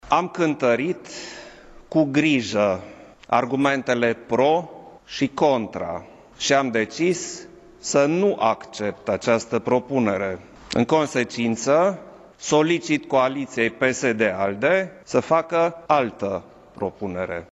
Președintele Klaus Iohannis a anunțat că nu acceptă propunerea PSD – ALDE ca Sevil Shhaideh să fie prim-ministru și a solicitat coaliției PSD — ALDE să facă o altă propunere de prim-ministru: